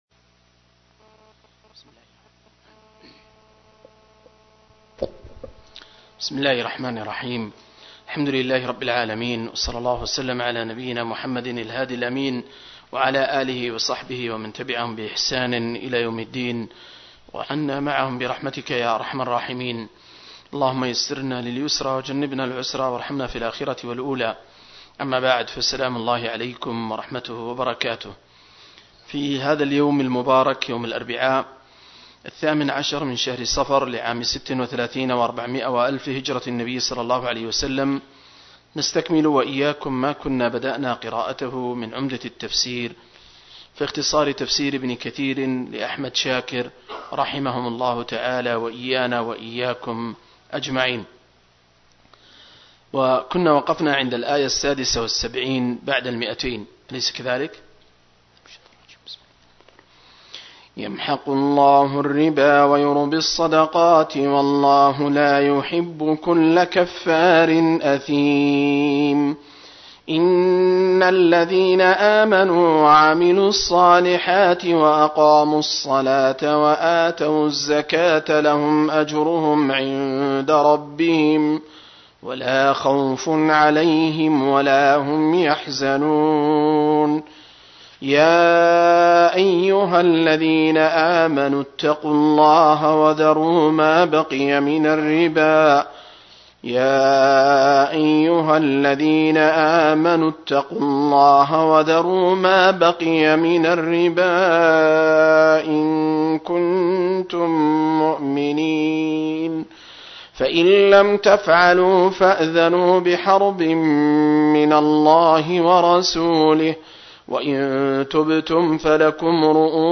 058- عمدة التفسير عن الحافظ ابن كثير – قراءة وتعليق – تفسيرالبقرة (الآيات 282-276)